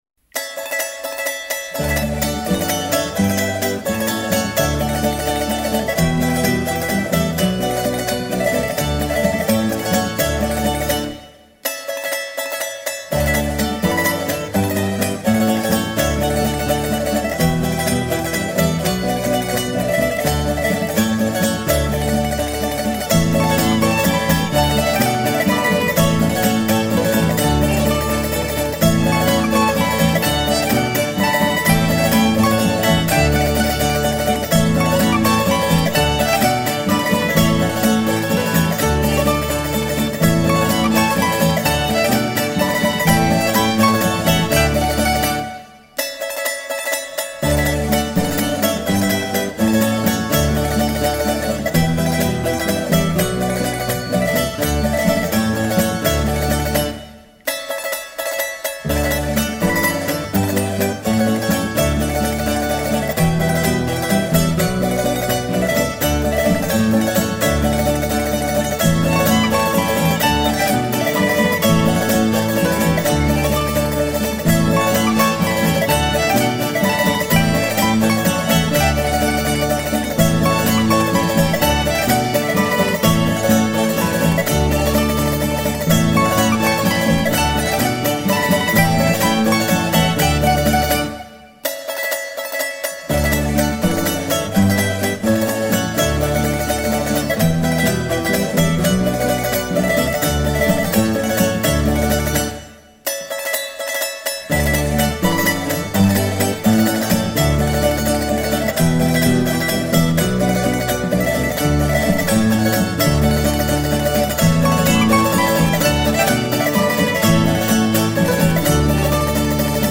"S. Gonçalo de Amarante" (instrumental)